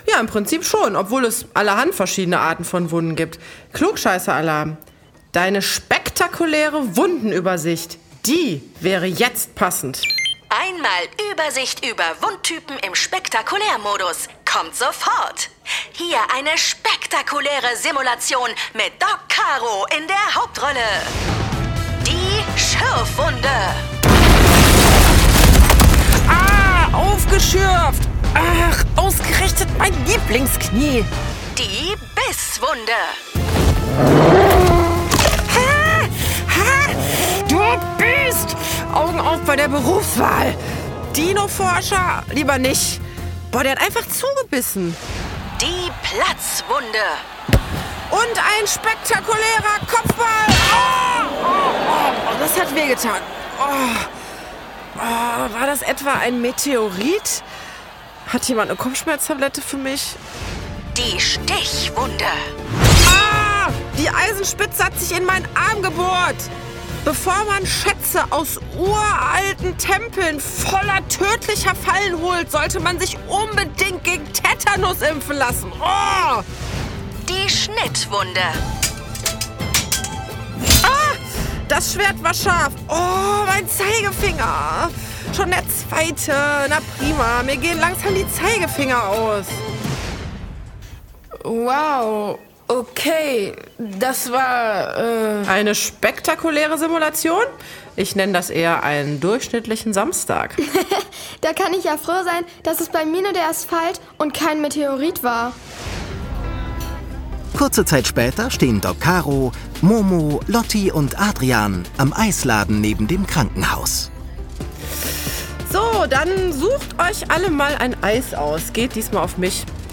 Doc Caro – Einsatz im Blut Ein Hörspiel über Blut, Wunden und wie sie heilen | Mit Tipps zur Ersten Hilfe und Wundversorgung Carola Holzner (Autor) Carola Holzner (Sprecher) Audio-CD 2024 | 1.